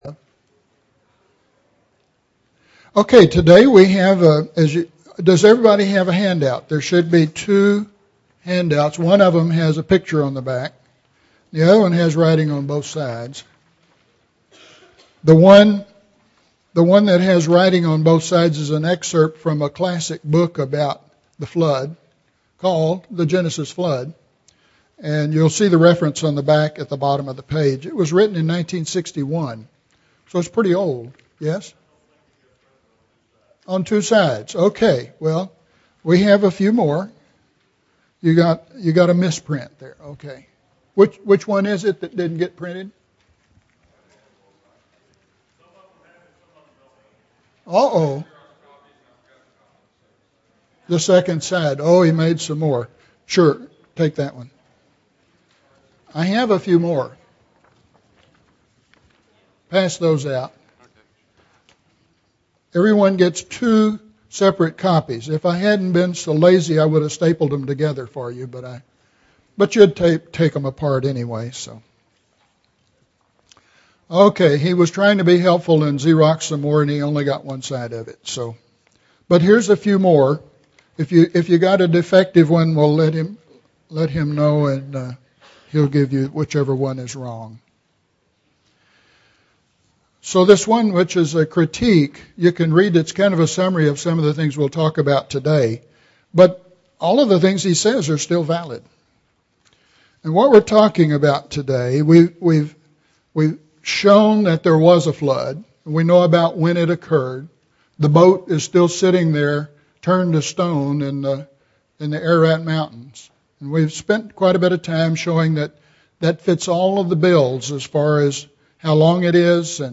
The Fossil Record in the Geological Strata (10 of 10) – Bible Lesson Recording
Sunday AM Bible Class